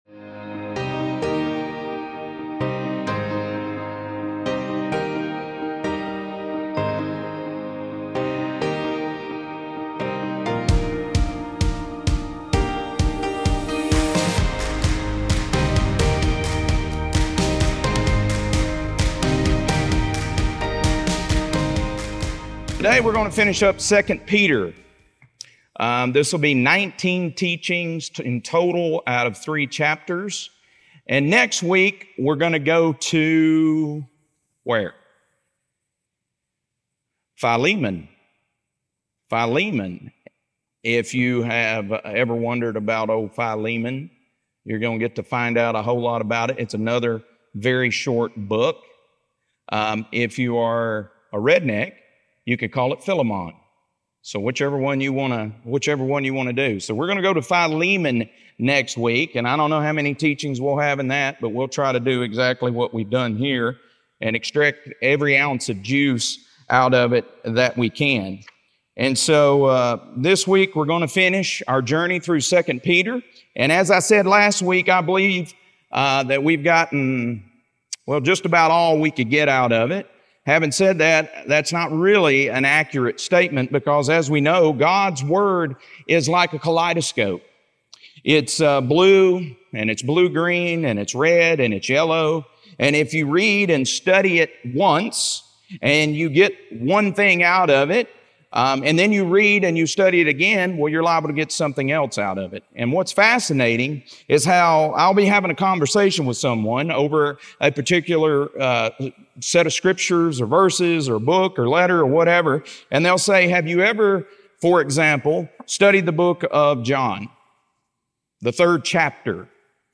2 Peter - Lesson 3F | Verse By Verse Ministry International